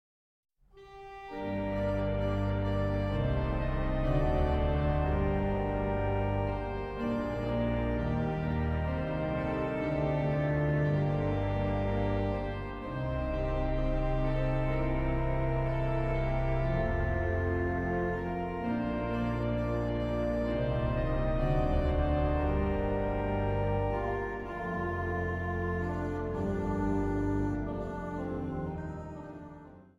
Instrumentaal
Instrumentaal | Synthesizer
Instrumentaal | Trompet